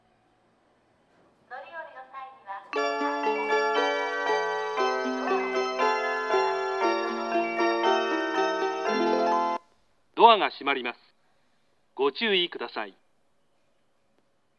接近放送
発車メロディー(Capuccino)   過去に武蔵野線で使われていた声質の放送です。